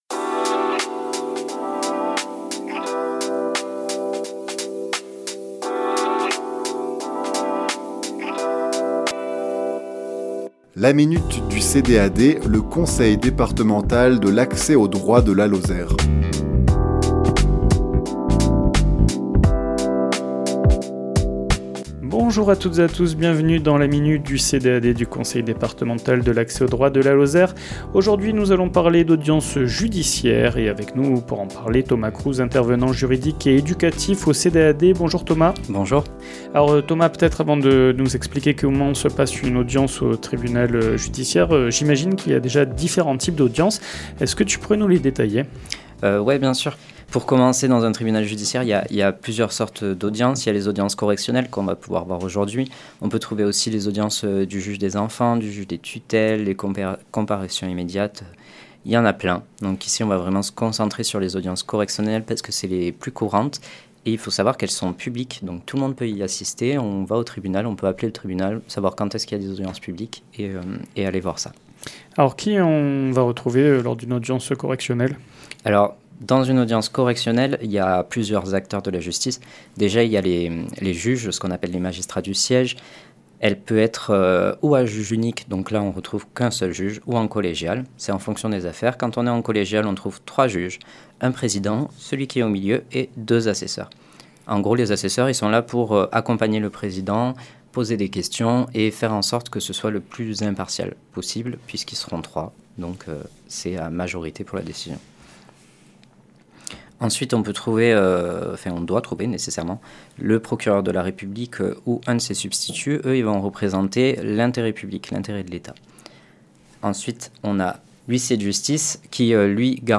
Chronique diffusée le lundi 9 décembre à 11h00 et 17h10